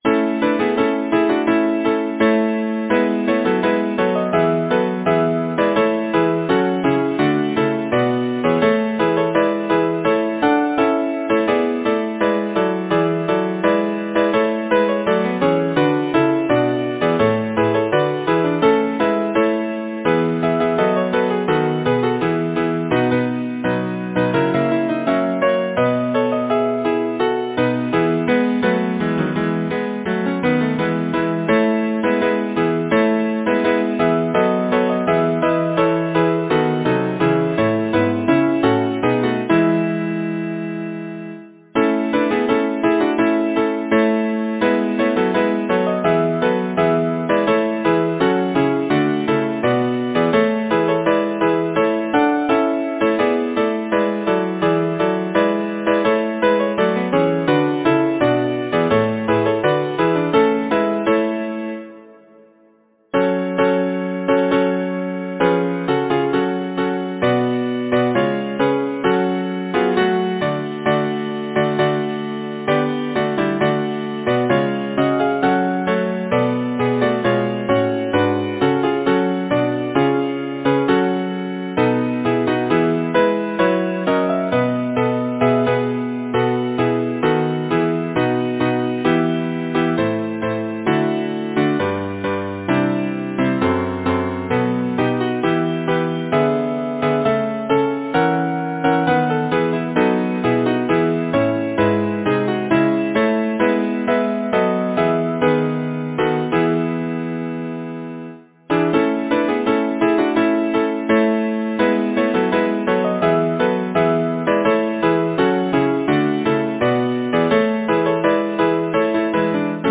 Title: The Christmas Fairies Composer: Charles Goodban Lyricist: Charles H. Hitchingscreate page Number of voices: 4vv Voicing: SATB Genre: Secular, Partsong
Language: English Instruments: A cappella
First published: 1852 J. Alfred Novello Description: Scored for 2 trebles, tenor, bass